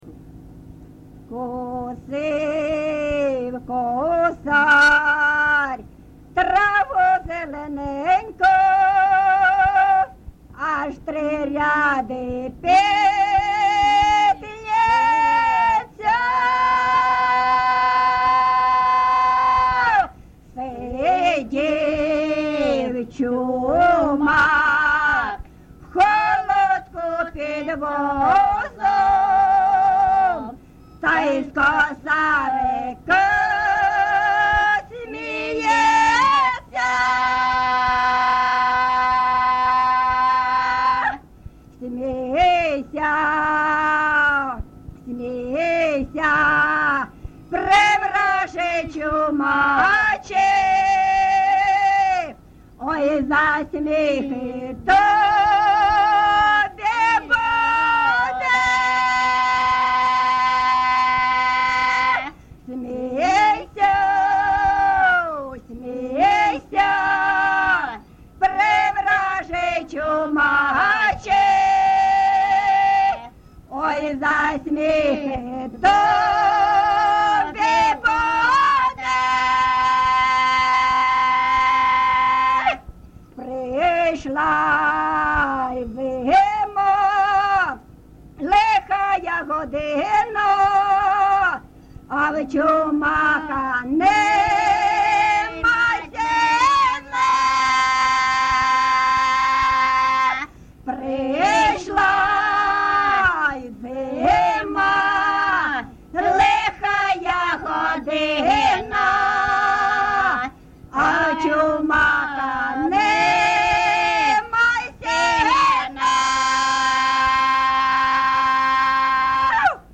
ЖанрЧумацькі
Місце записус. Свято-Покровське, Бахмутський район, Донецька обл., Україна, Слобожанщина